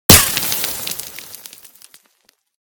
/ gamedata / sounds / material / bullet / collide / sand03gr.ogg 37 KiB (Stored with Git LFS) Raw History
sand03gr.ogg